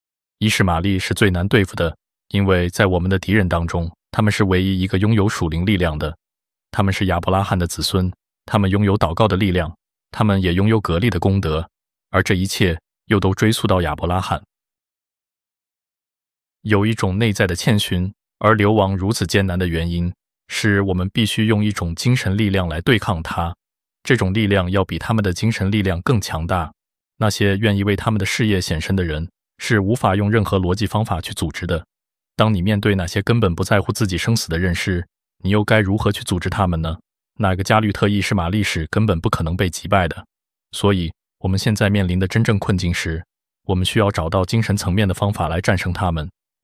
الحاخام اليهودي يتحدث عن المسلمين وإيمانهم!
هذا الصوت يتناول حديثاً من حاخام يهودي عن المسلمين وديانتهم، حيث يشارك فهمه للإسلام ويستعرض معتقدات المسلمين وممارساتهم.